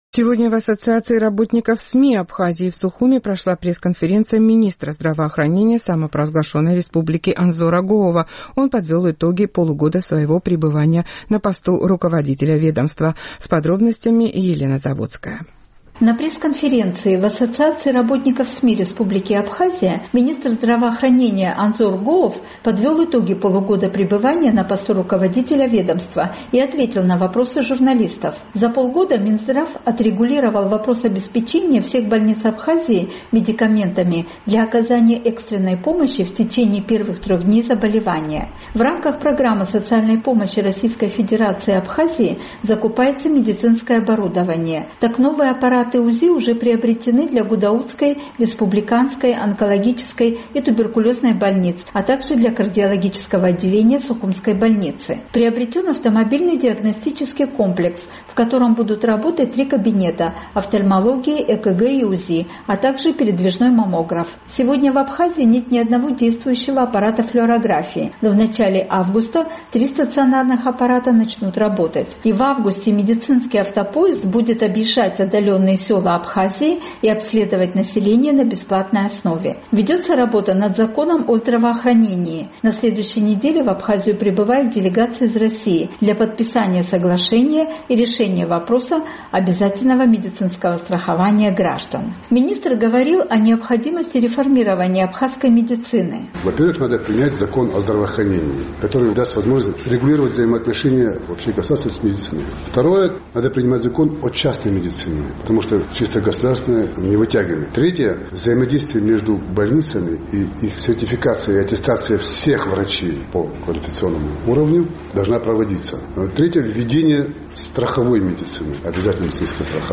Сегодня в Сухуме прошла пресс-конференция министра здравоохранения Анзора Гоова. Он подвел итоги полугода своего пребывания на посту руководителя ведомства.